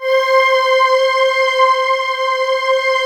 Index of /90_sSampleCDs/USB Soundscan vol.28 - Choir Acoustic & Synth [AKAI] 1CD/Partition D/23-SOMEVOICE